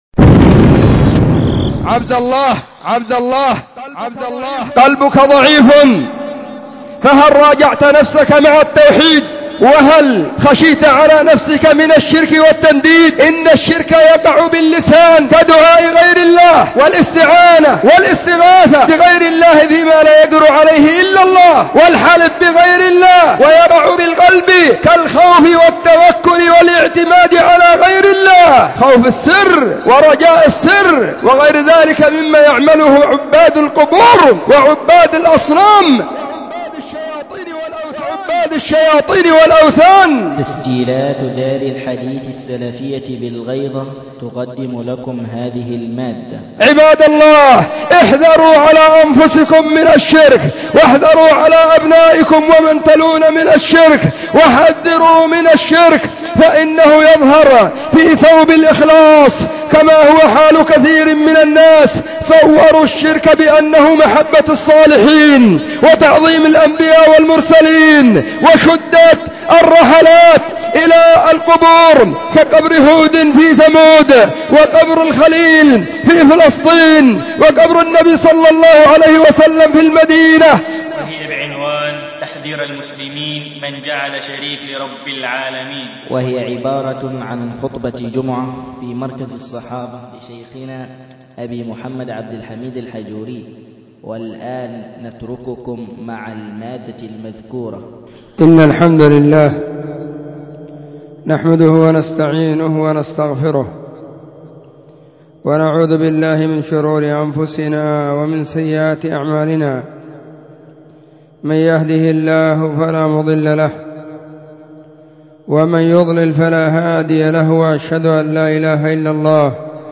📢 وكانت في مسجد الصحابة بالغيضة، محافظة المهرة – اليمن.
الجمعة 16 محرم 1442 هــــ | الخطب المنبرية | شارك بتعليقك